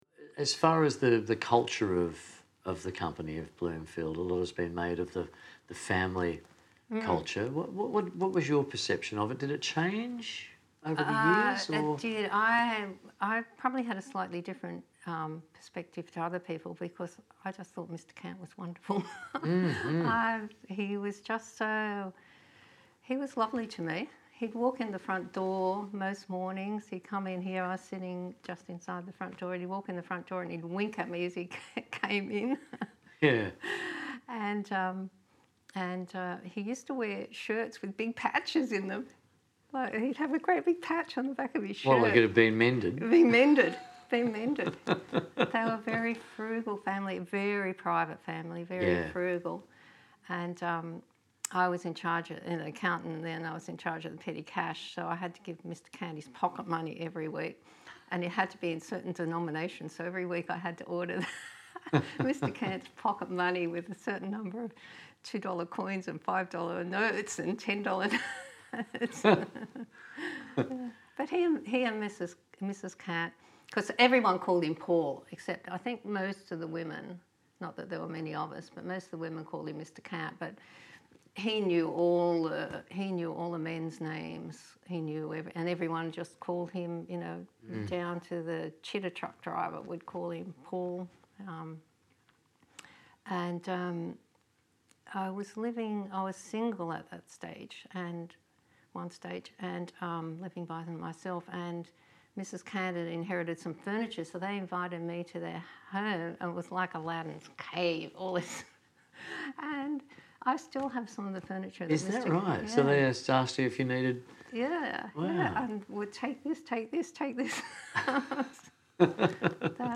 a series of interviews